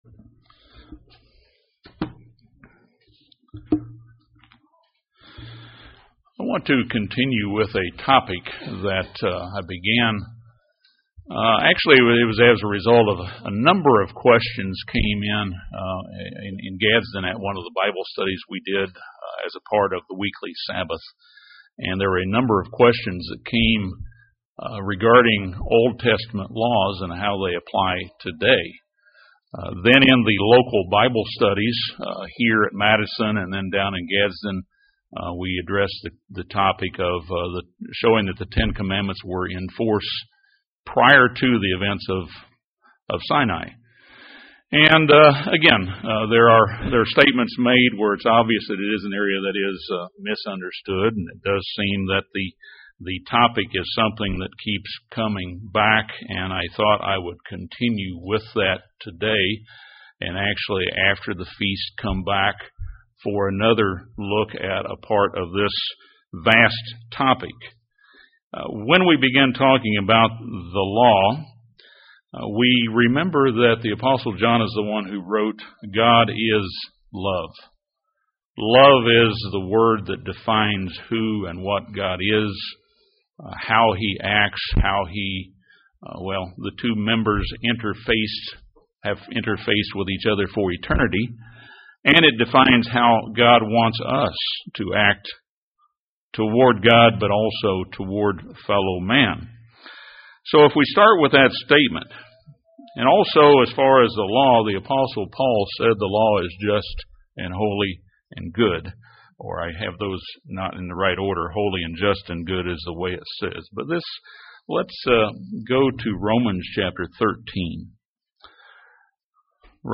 This sermon discusses the law of God and the main categories of commandments, statutes and judgments. It also considers the question of how we apply OT law during the NT age of the church.